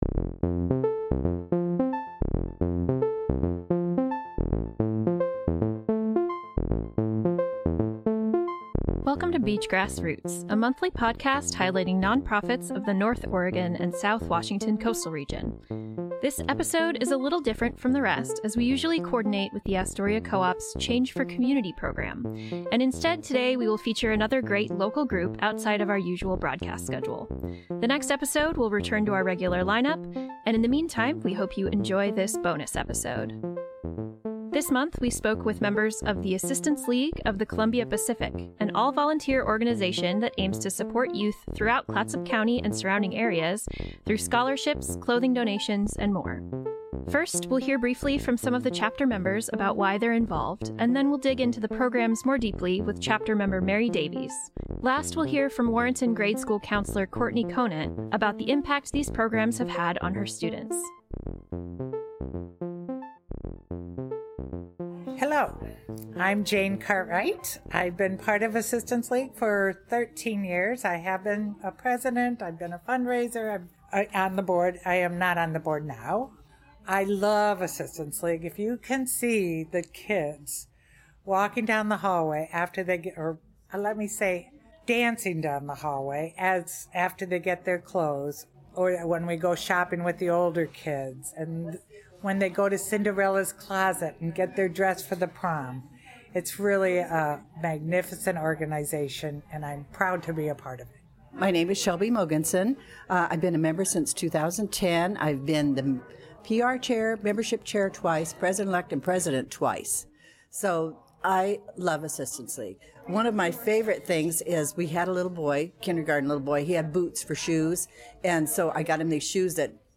In this bonus episode of Beach Grass Roots, we talk with members of the Assistance League of the Columbia Pacific, an organization that works to support youth throughout Clatsop County and the surrounding communities.